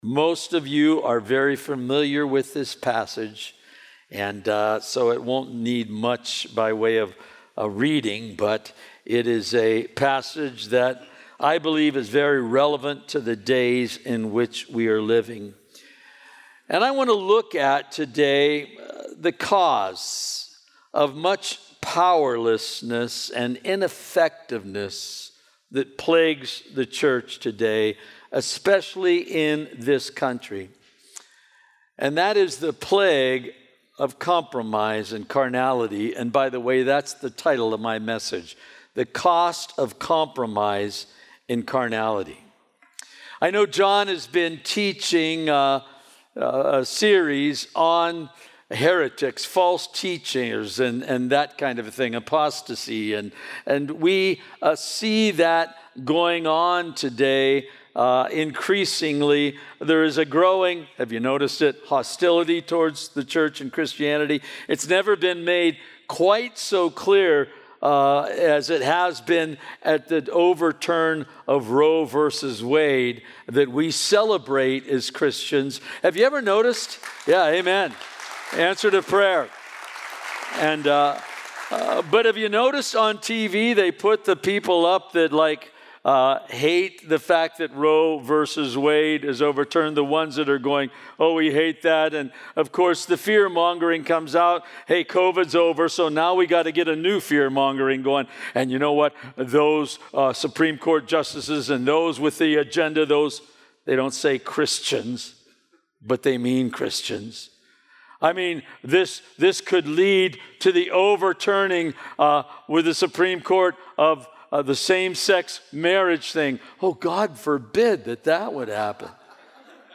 A verse-by-verse expository sermon through Genesis 19:1-38